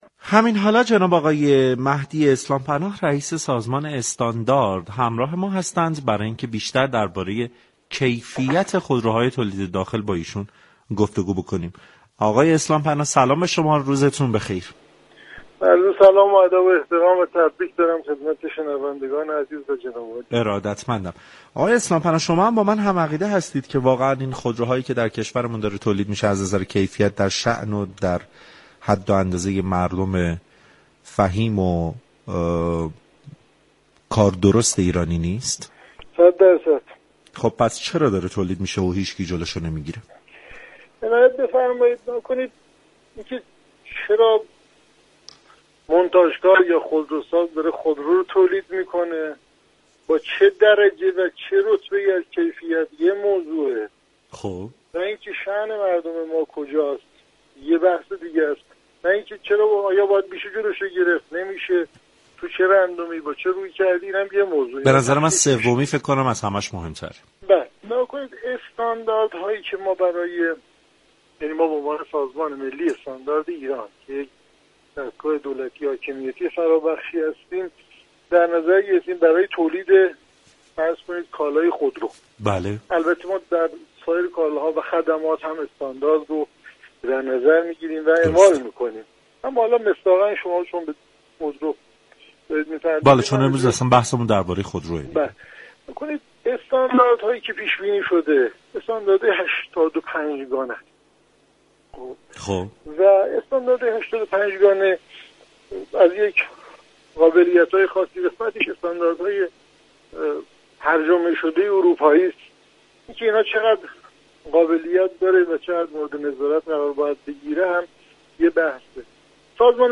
به گزارش پایگاه اطلاع رسانی رادیو تهران، مهدی اسلام پناه رئیس سازمان ملی استاندار در گفت و گو با برنامه سعادت آباد با تاكید بر اینكه خودروهای تولید داخل در شأن مردم ایران نیست گفت: سازمان ملی استاندارد ایران به عنوان یك دستگاه حاكمیتی استاندارد 85 گانه اجباری را برای تولید خودرو در نظر گرفته است .